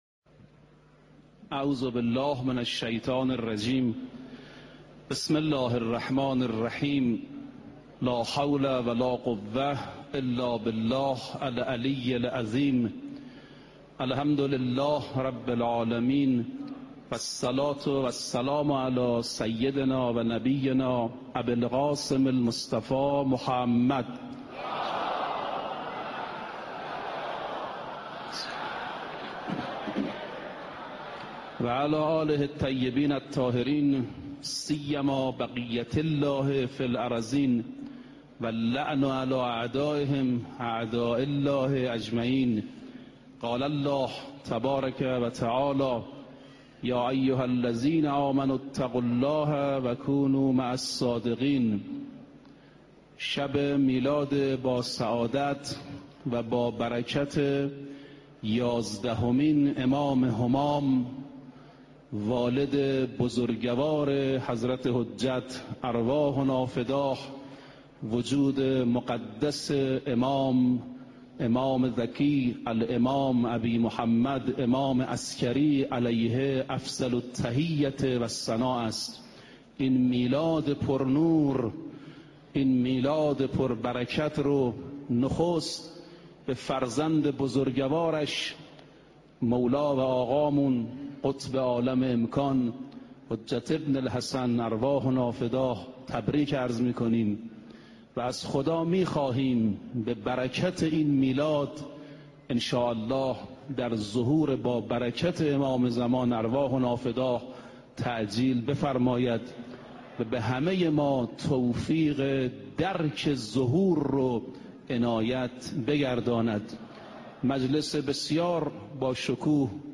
صوت سخنرانی مذهبی و اخلاقی در این سخنرانی قصد داریم به ۵ اقدام مهم امام حسن عسکری (ع) در حفاظت از شیعه بپردازیم.